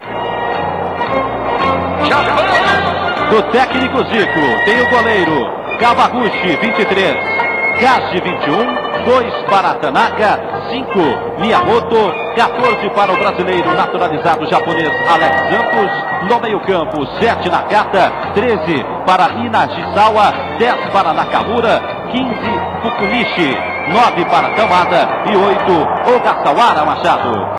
その、試合前、サンパウロのRadio Globoでの選手紹介。豪快です。音量注意でどうぞ。